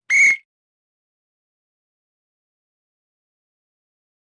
Свисток инспектора ГИБДД